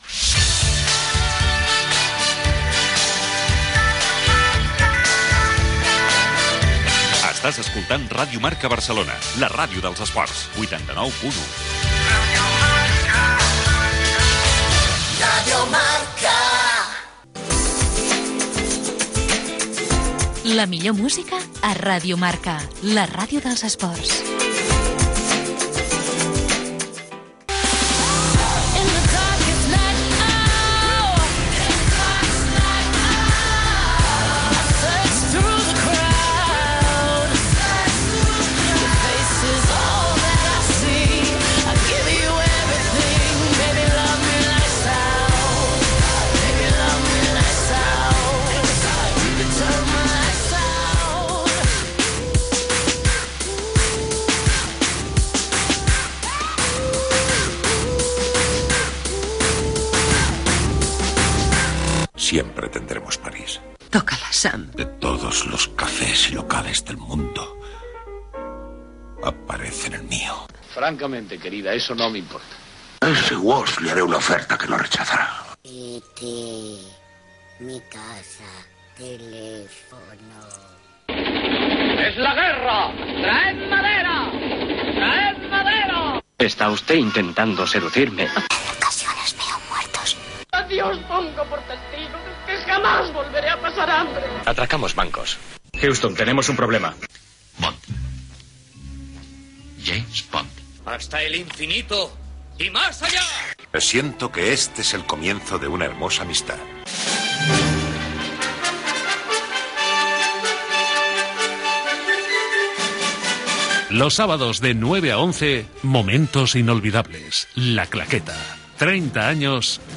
Indicatiu de l'emissora, tema musical, promoció del programa "La claqueta", indicatiu
FM